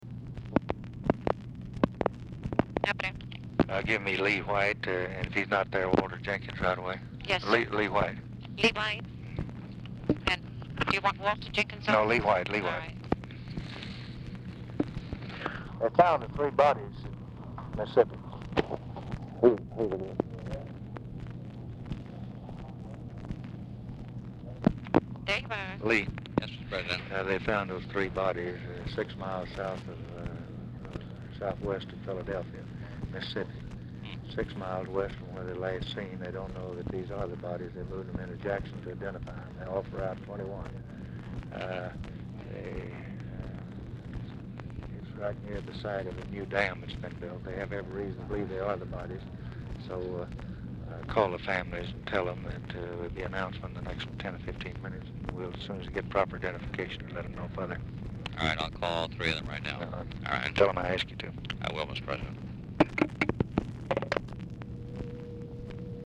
Telephone conversation # 4694, sound recording, LBJ and LEE WHITE, 8/4/1964, time unknown | Discover LBJ
OFFICE CONVERSATION PRECEDES CALL; LBJ IS MEETING IN CABINET ROOM WITH CONGRESSIONAL LEADERSHIP, MCNAMARA, RUSK, MCGEORGE BUNDY AT TIME OF CALL
Format Dictation belt